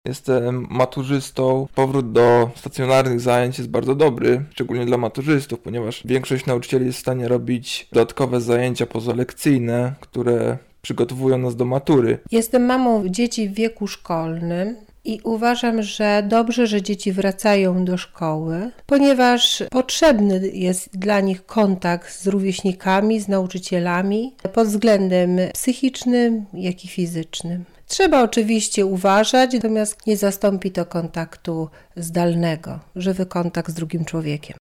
A co na ten temat sądzą dzieci i ich rodzice?
SONDA